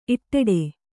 ♪ itṭṭeḍe